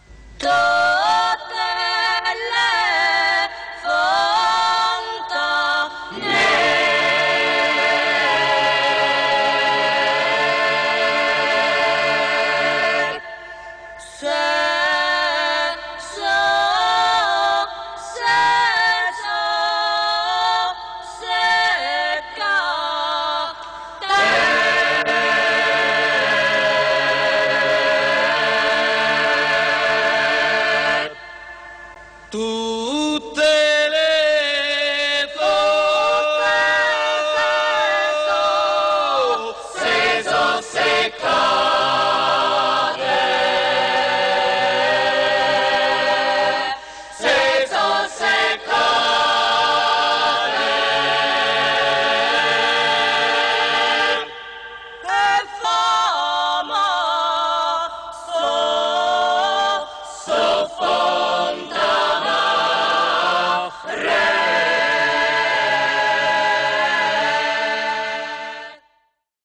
Original Track Music (1.00)